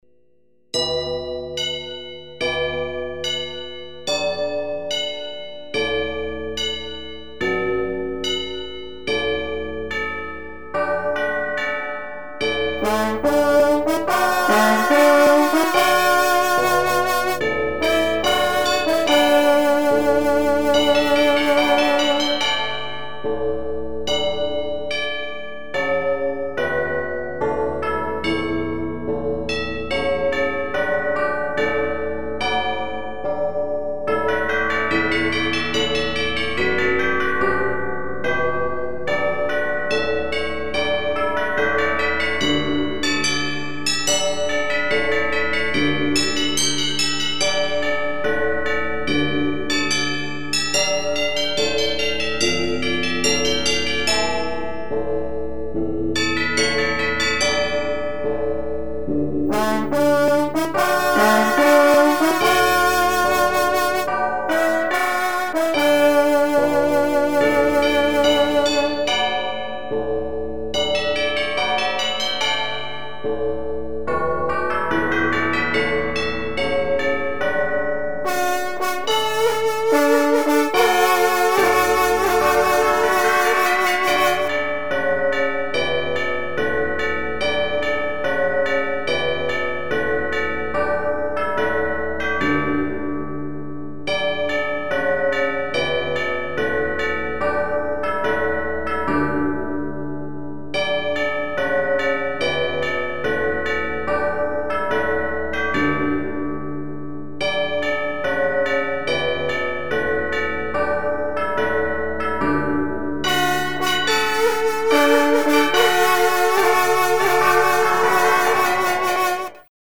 dans tous les enregistrements, le fond sonore est sur le canal gauche, la partie à travailler sur le canal droit !
Partie_A+B_solo_2_canal_droit.mp3